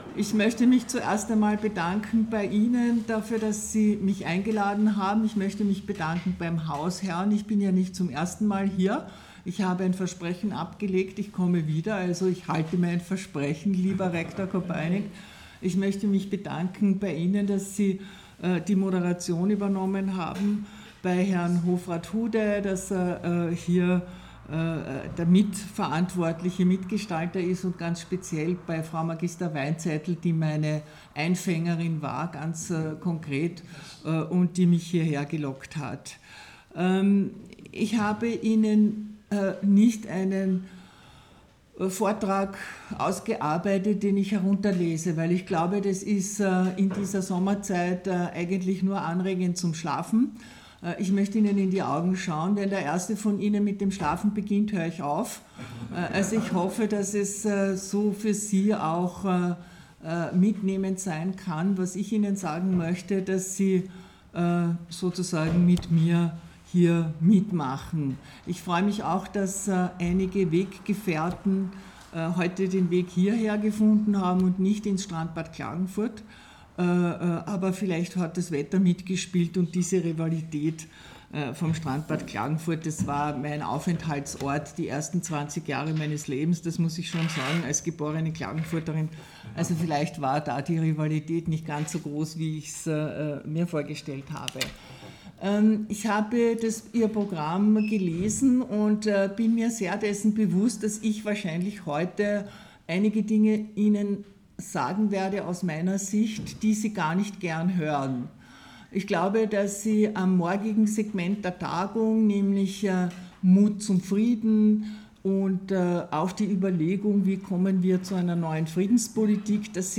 Bei der Internationalen Sommertagung des KAVÖ im Bildungshaus Sodalitas in Tainach/Tinje sprach die frühere Diplomatin und ehemalige österreichische Europa- und Außenministerin Dr.in Ursula Plassnik am 21. August 2025 über die Zukunft Europas. In ihrem Vortrag betonte sie, dass Europa Verantwortung übernehmen, seine Sicherheit und Wettbewerbsfähigkeit stärken und Demokratie aktiv leben müsse.